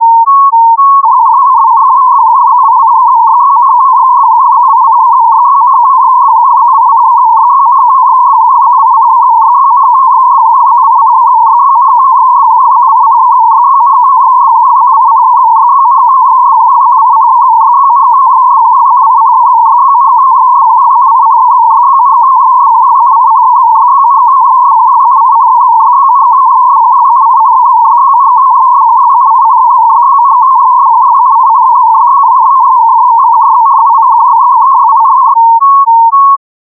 Contestia_Mode.mp3